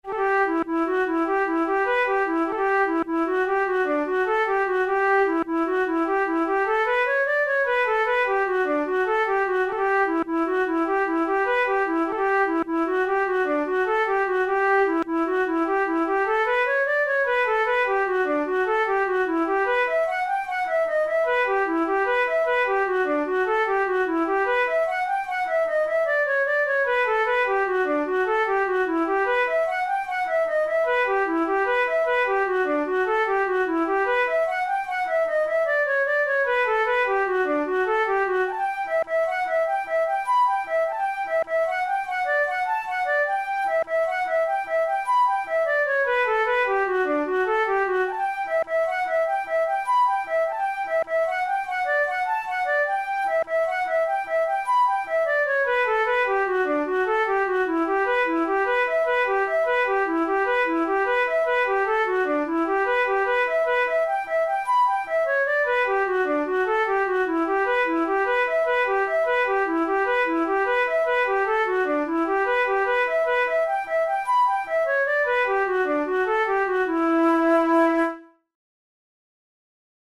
InstrumentationFlute solo
KeyE minor
Time signature6/8
Tempo100 BPM
Jigs, Traditional/Folk
Traditional Irish jig